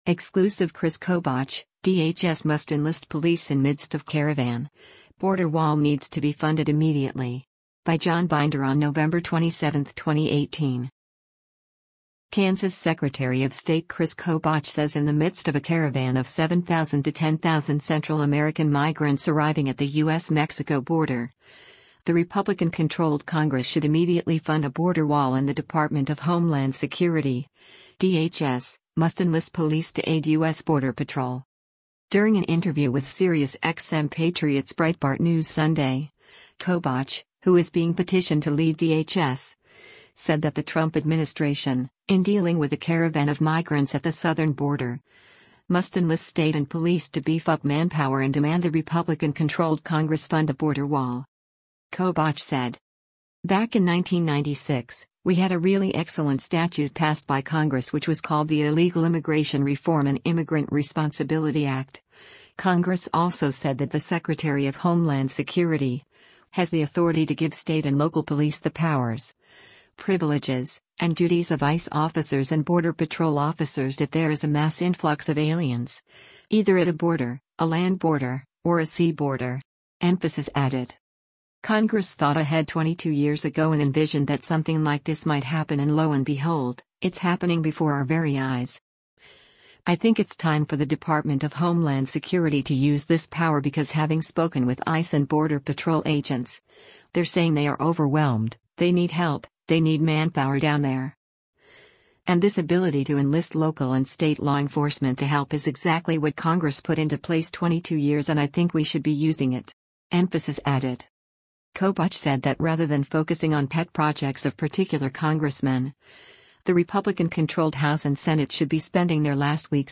During an interview with SiriusXM Patriot’s Breitbart News Sunday, Kobach — who is being petitioned to lead DHS — said that the Trump administration, in dealing with a caravan of migrants at the southern border, must enlist state and police to beef up manpower and demand the Republican-controlled Congress fund a border wall.